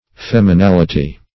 Meaning of feminality. feminality synonyms, pronunciation, spelling and more from Free Dictionary.
Feminality \Fem`i*nal"i*ty\, n.
feminality.mp3